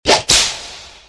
9.3 Cartoon Whip
9-3-cartoon-whip.mp3